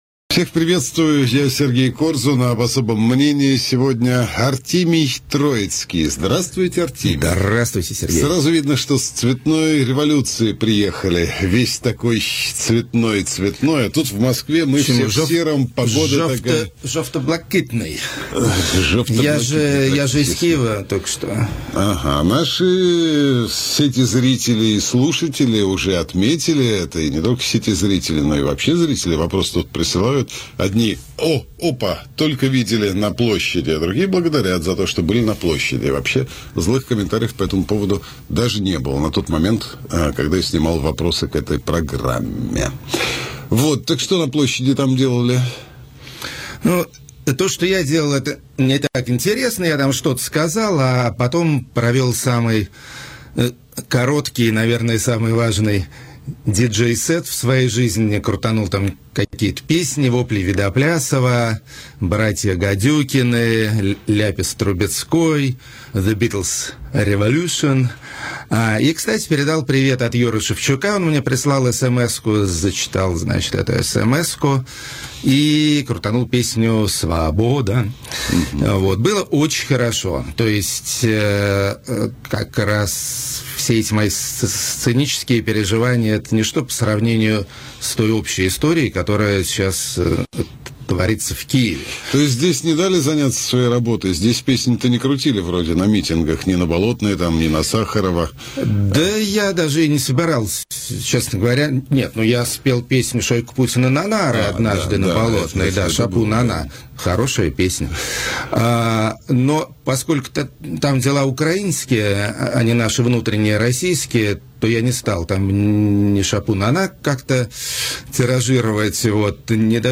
Взгляд заезжего "москаля" на события в Киеве. Интервью А.Троицкого. 16.12.13.